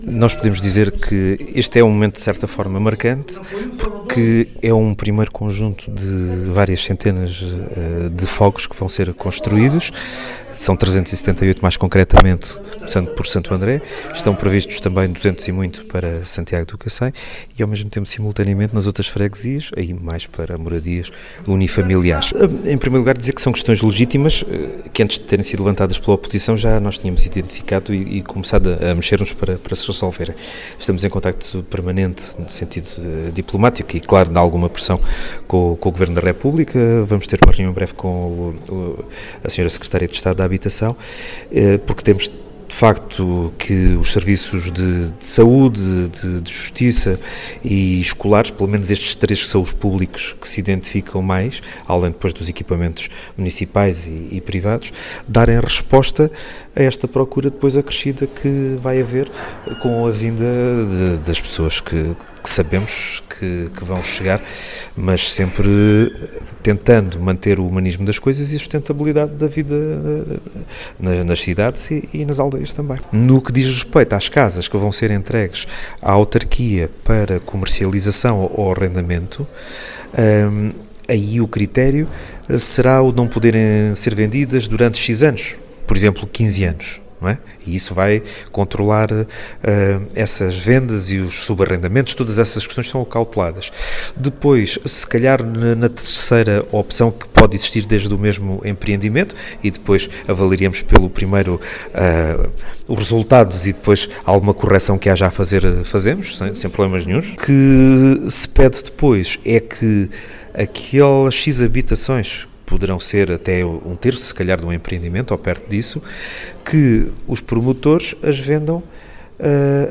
Declarações do Presidente da Câmara Municipal